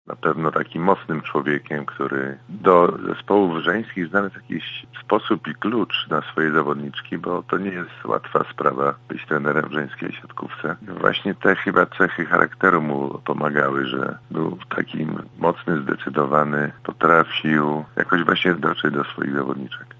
Wspomina były siatkarz Tomasz Wójtowicz, złoty medalista z Igrzysk Olimpijskich z Montrealu.